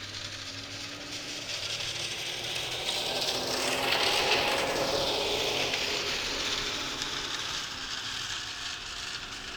Electric Subjective Noise Event Audio File (WAV)
mcgill_electric_2007.wav